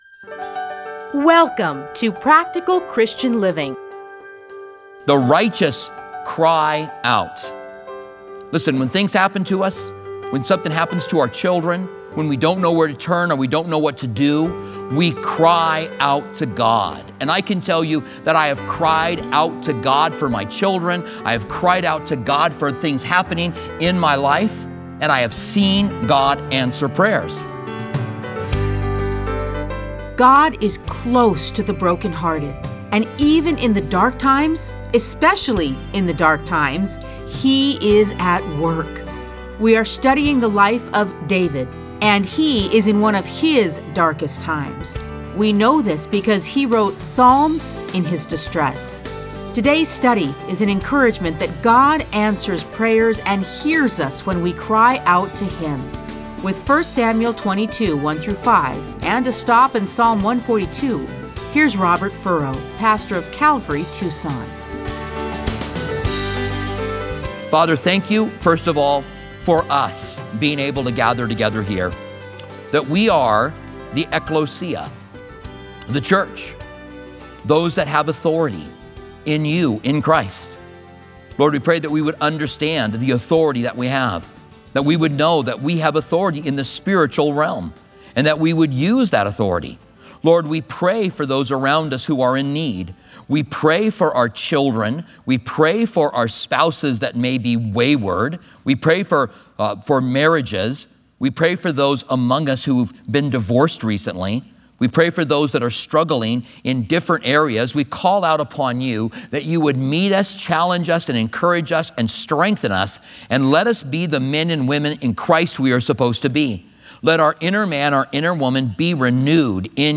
Listen to a teaching from 1 Samuel 22:1-5.